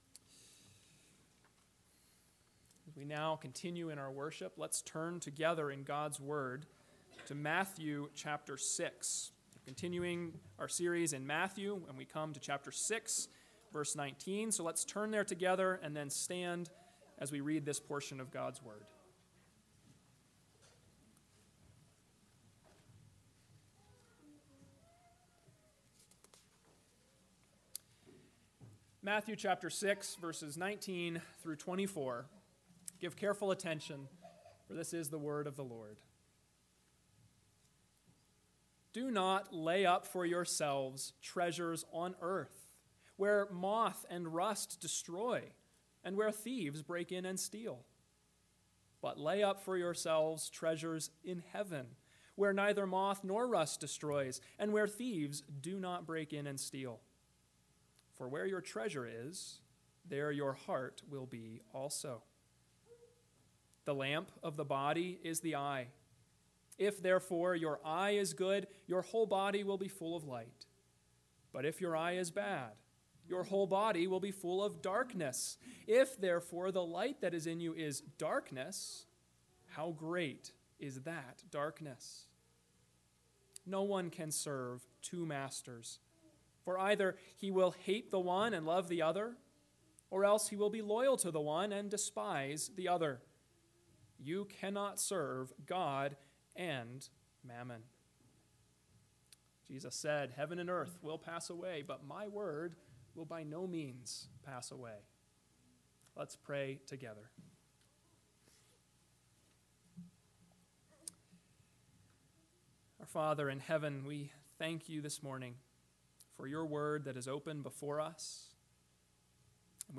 AM Sermon – 4/16/2023 – Matthew 6:19-24 – Where Is Your Treasure?